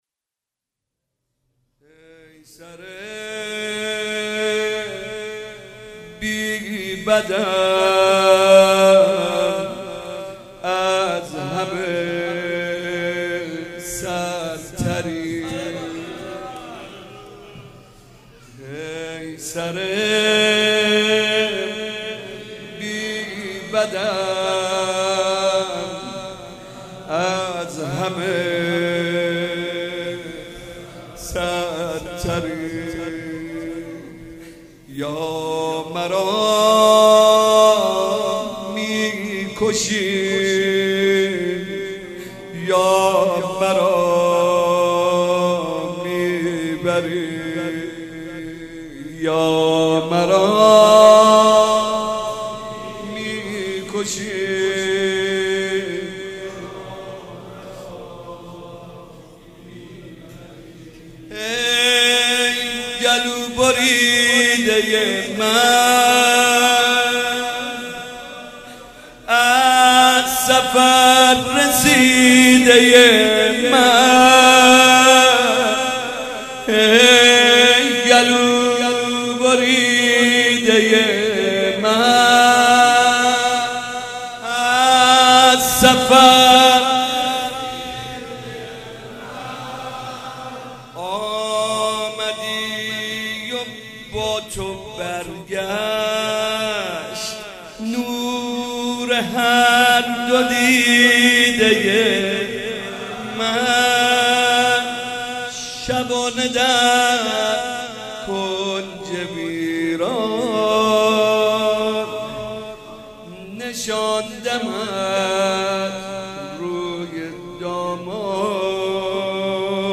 مراسم شب سوم محرم الحرام سال 1395